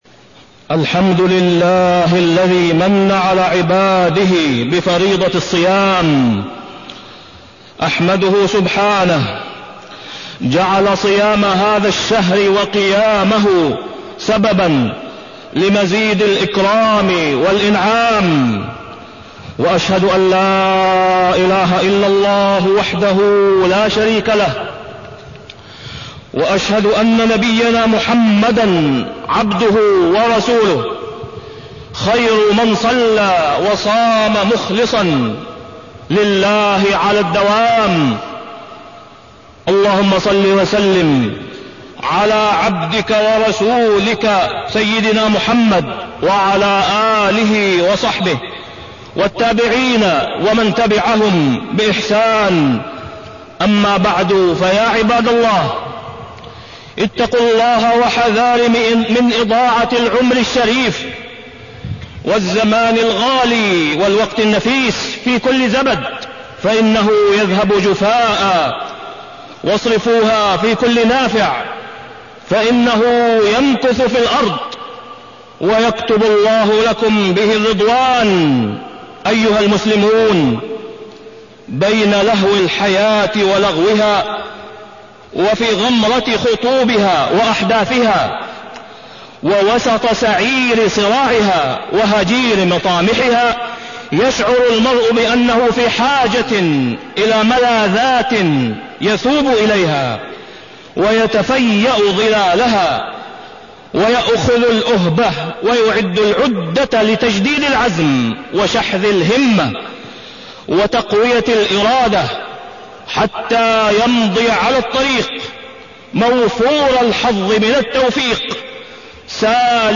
تاريخ النشر ١٥ رمضان ١٤٢٢ هـ المكان: المسجد الحرام الشيخ: فضيلة الشيخ د. أسامة بن عبدالله خياط فضيلة الشيخ د. أسامة بن عبدالله خياط تغير المسار والإرادة